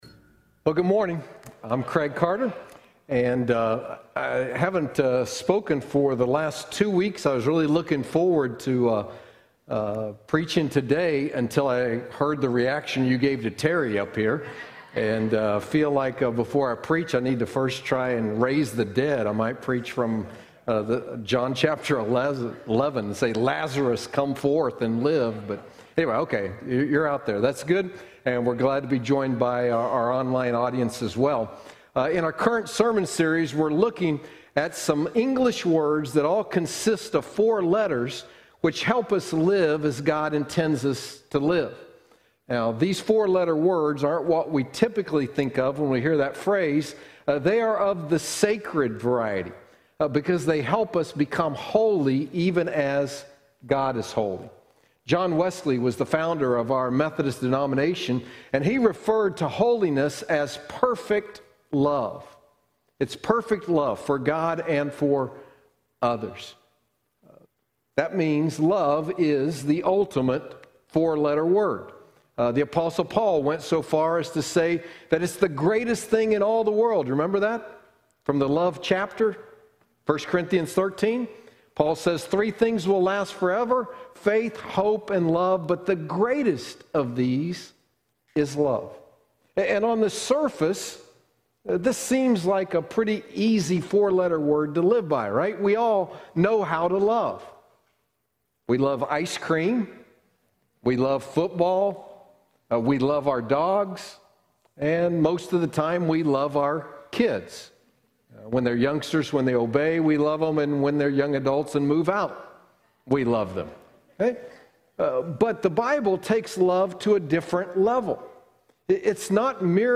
Sacred 4-Letter Words Service Type: Sunday Morning Download Files Notes Bulletin « Sacred 4-Letter Words